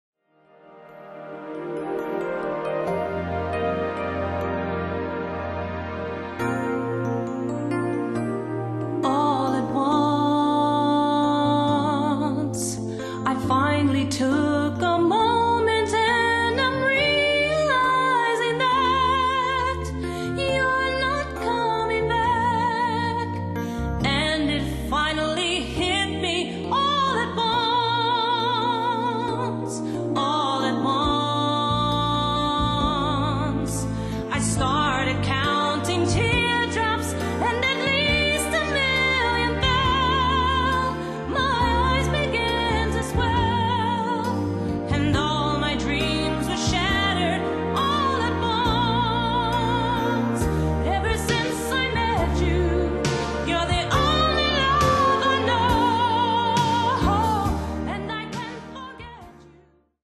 Multiplex (mit und ohne Gesang)